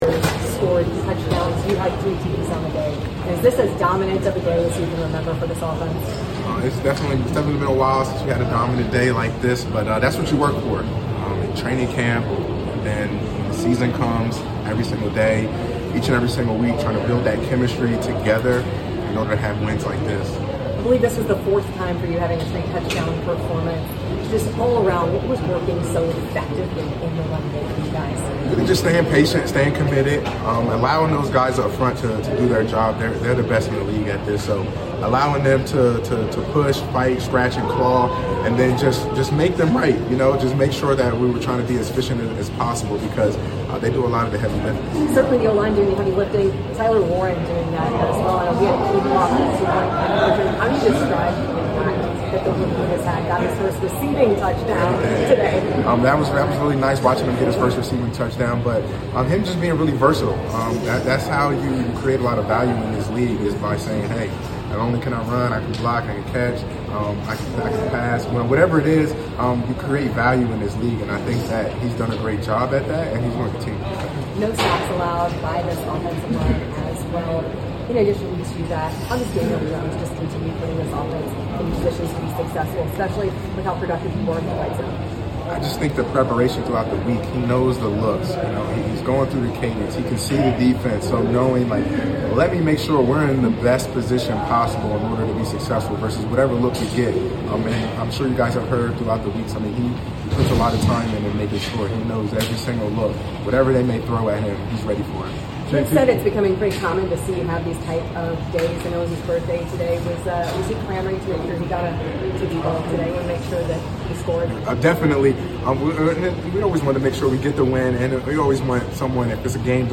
Indianapolis Colts Running Back Jonathan Taylor Postgame Interview after defeating the Las Vegas Raiders at Lucas Oil Stadium.